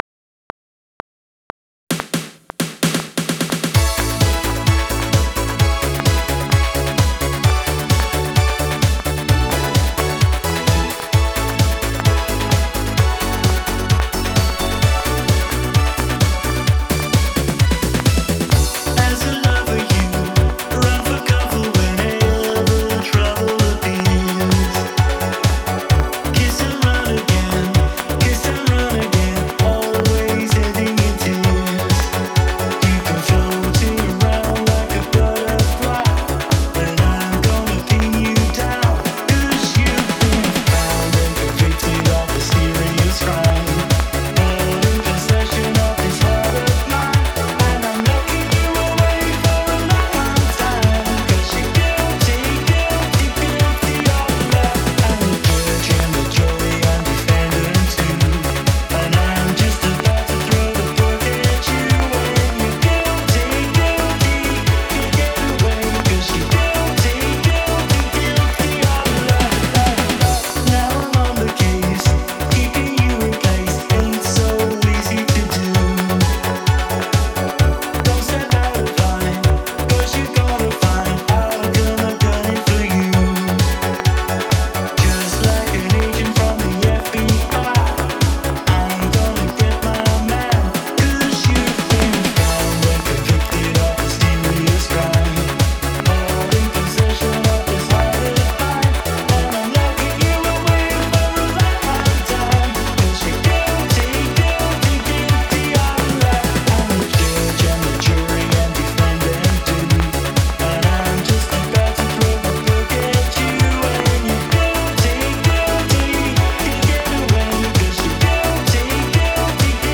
UK pop artist
Radio Edit